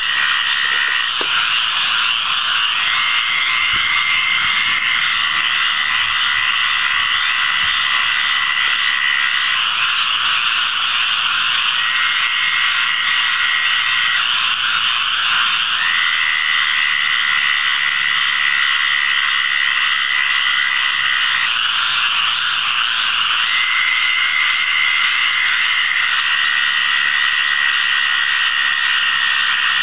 This is a very large chorus on a warm rainy night, and here you'll hear Bufo terrestris, and Hyla squirella.